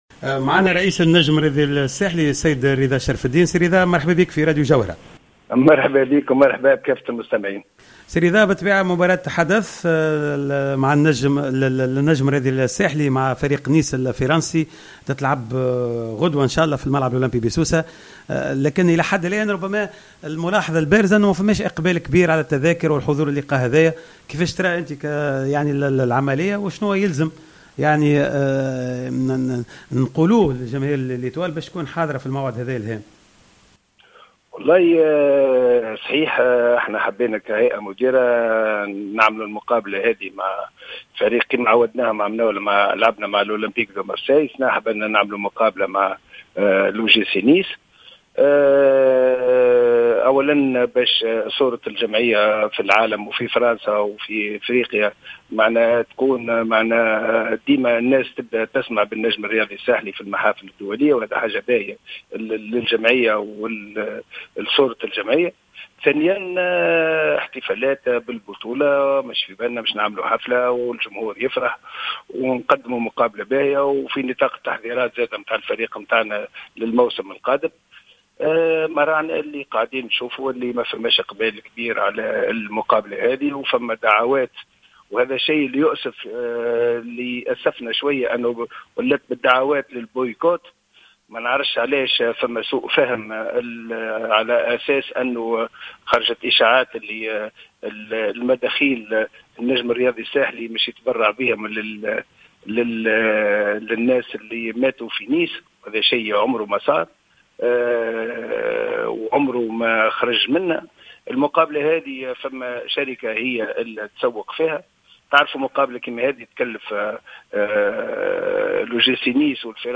تحدث رئيس النجم الرياضي الساحلي رضا شرف الدين في حوار مع جوهرة أف أم عن مواجهة...